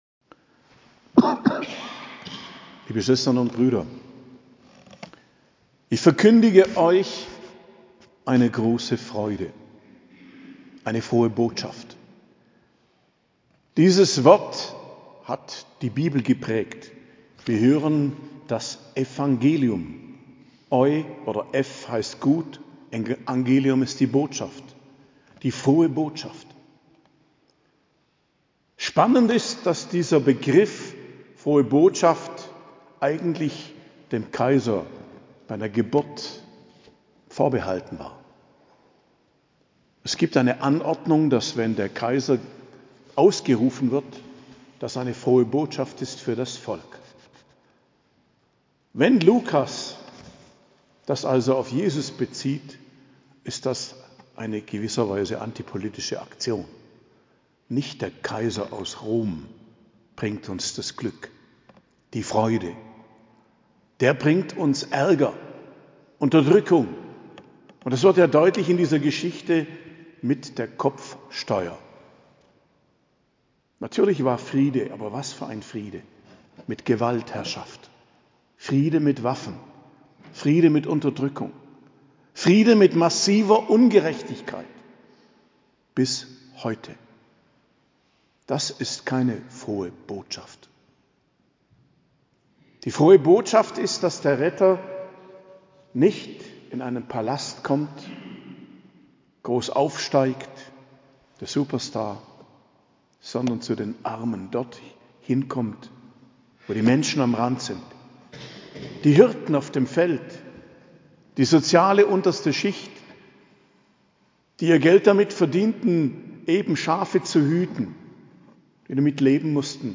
Predigt zu Weihnachten - Hochfest der Geburt des Herrn - In der Heiligen Nacht, 24.12.2023